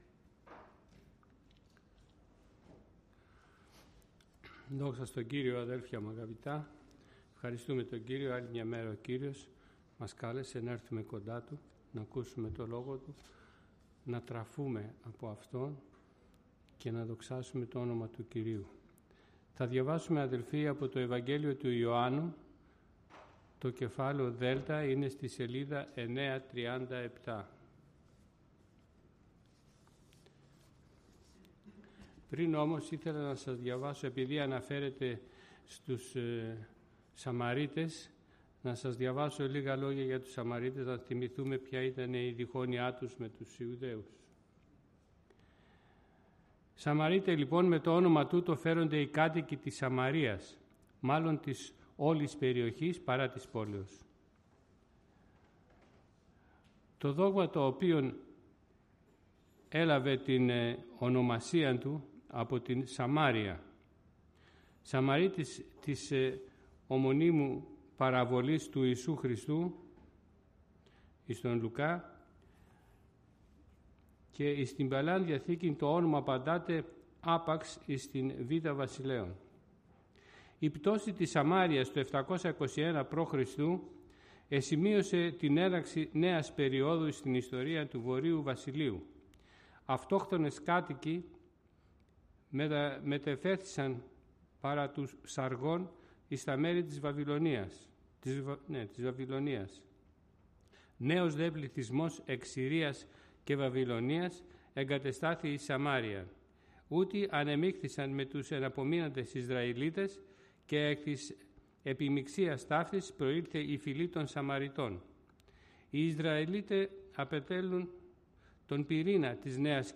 Κήρυγμα Ευαγγελίου